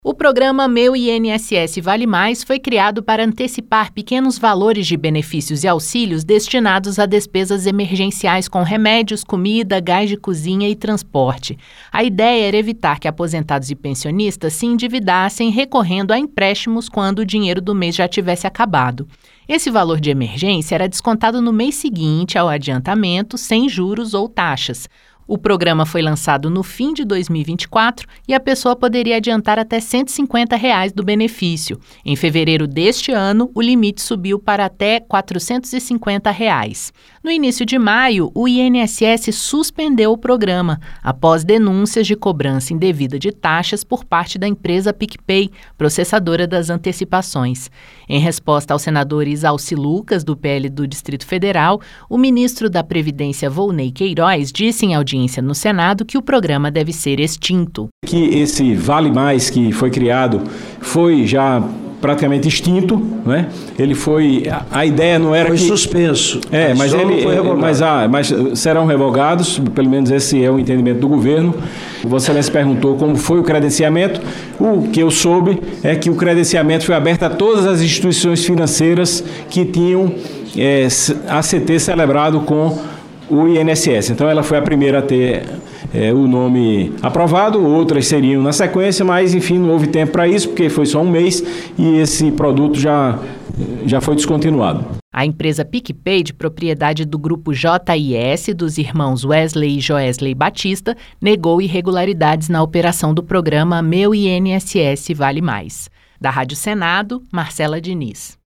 No início de maio, o INSS suspendeu o programa de antecipação de valores de benefícios, "Meu INSS Vale+", após denúncias de cobrança indevida de taxas por parte da empresa PicPay, que negou irregularidades. Em resposta ao senador Izalci Lucas (PL-DF), o ministro da Previdência, Wolney Queiroz, disse em audiência no Senado que o programa deve ser extinto.